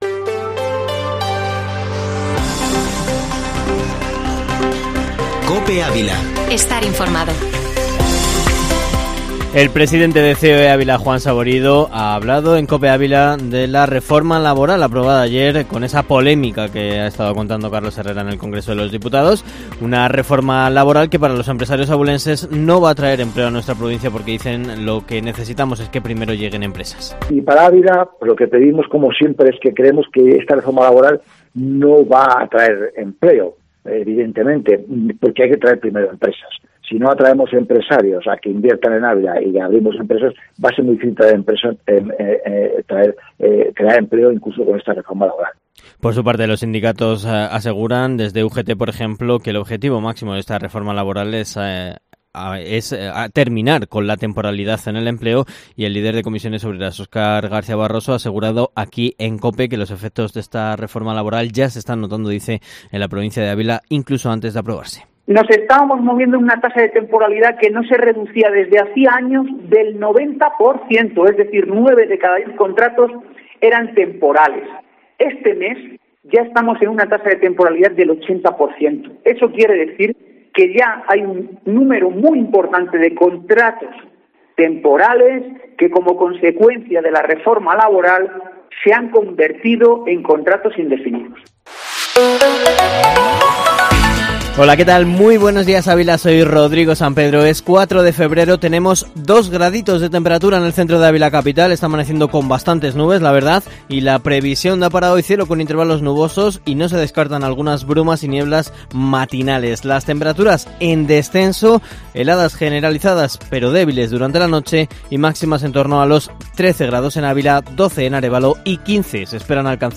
Informativo Matinal Herrera en COPE Ávila -4-feb